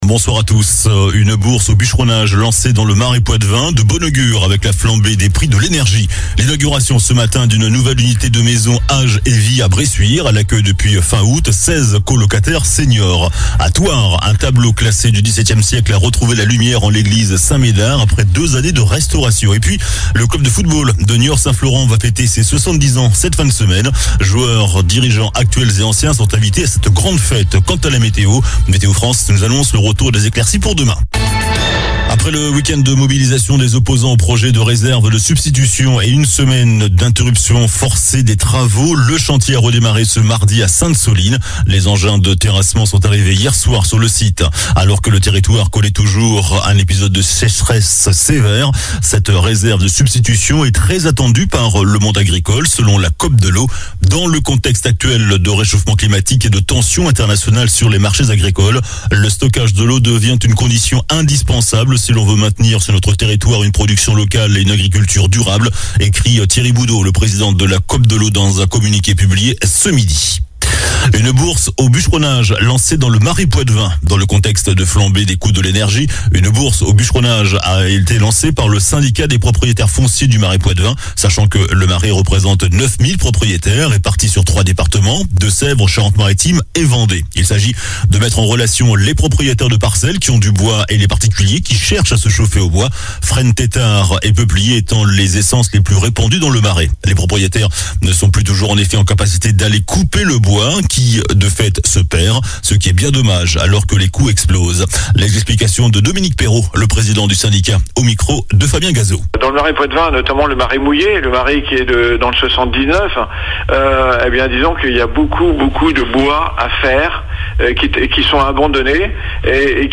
Journal du mardi 8 novembre (soir)